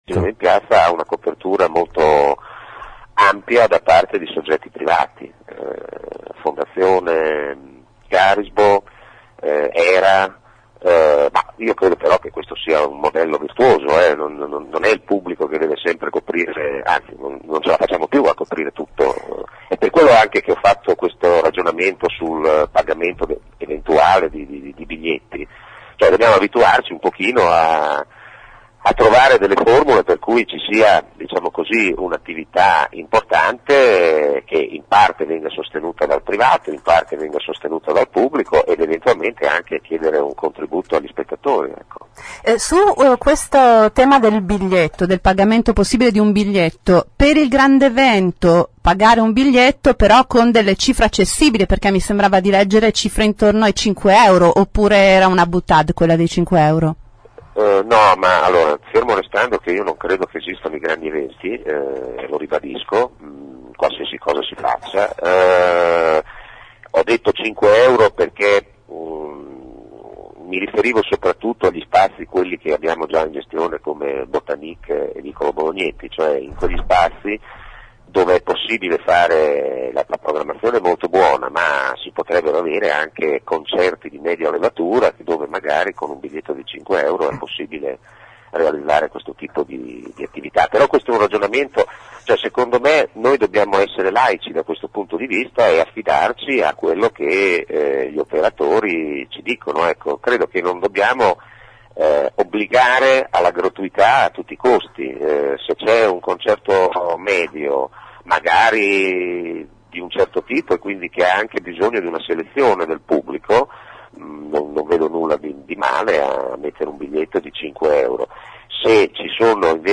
23 giu. – “Il Cinema in Piazza deve essere gratis, ma non facciamo della gratuità un moloch“, così l’assessore alla cultura Alberto Ronchi, questa mattina ai nostri microfoni.